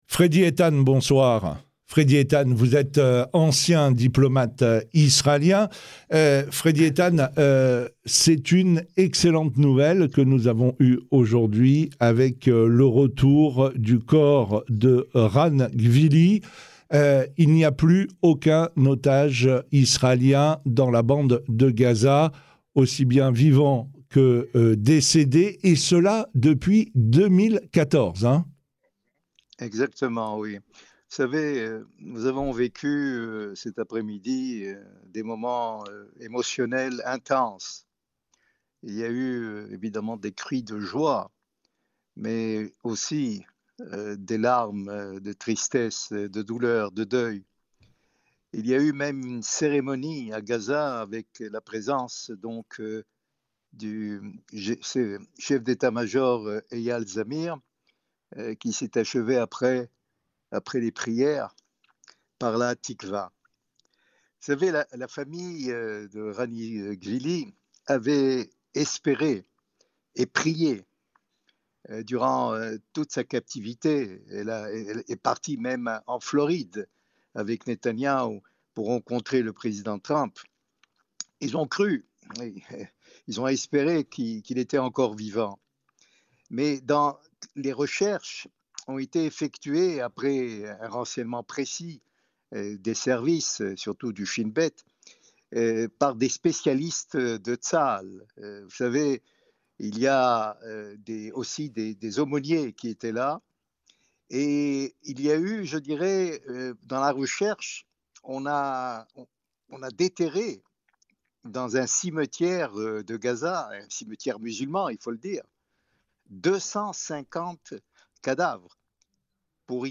Radio Shalom France au micro